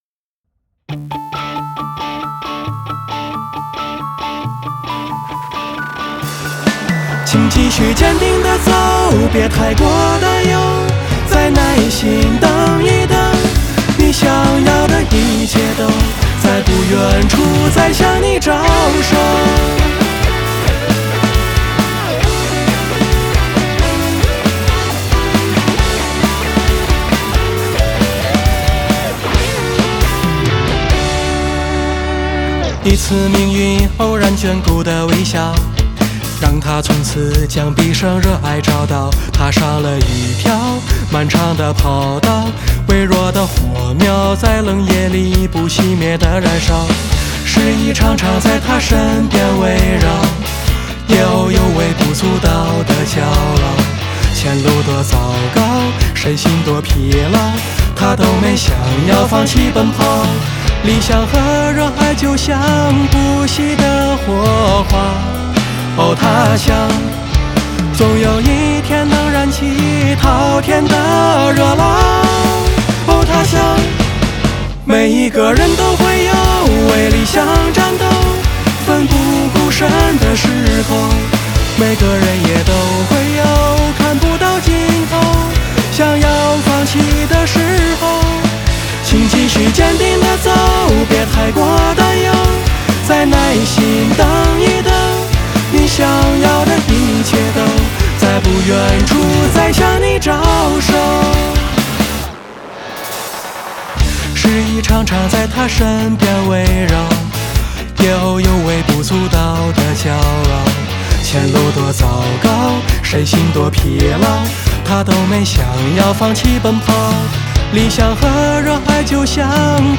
Today, I'm here to share with you an inspiring rock song called "Unextinguished Spark". The style of this song is inspired by many classic Japanese anime theme songs, encouraging everyone to hold on to their dreams an...